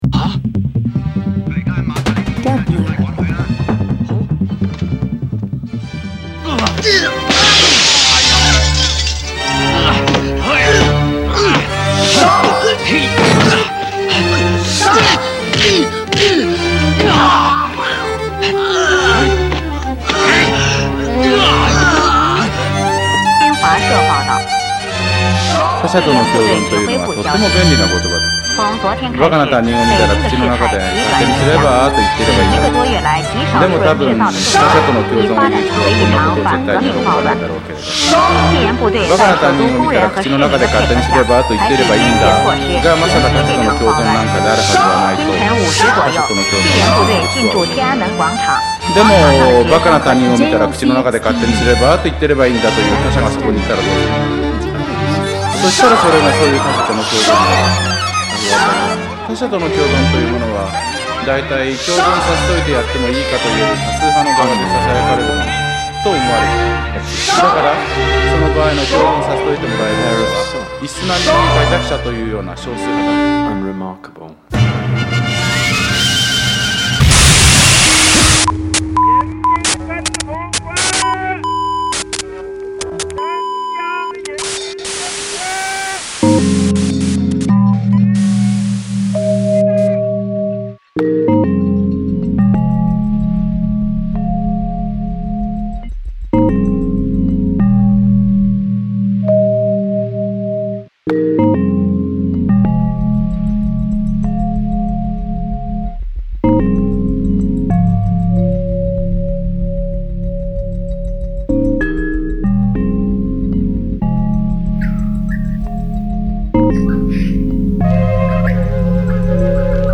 Avant-Garde Electronic Experimental